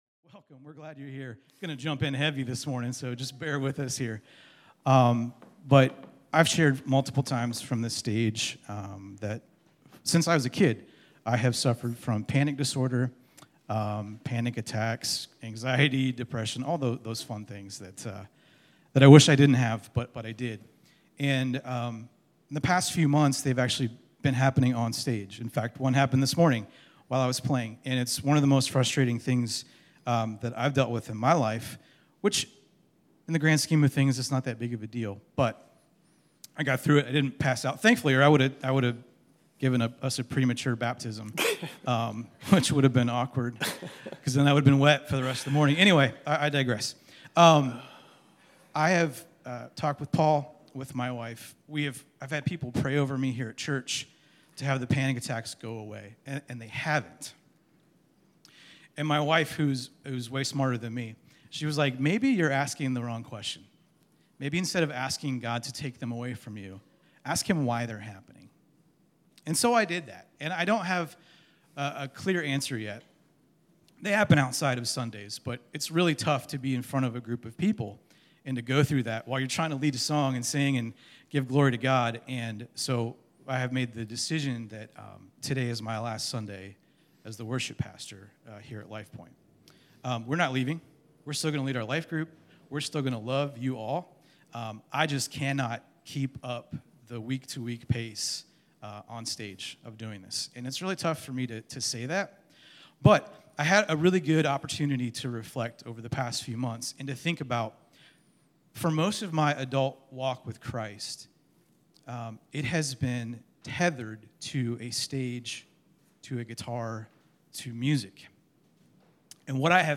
Due to technical difficulties the entirety of the message was not recorded.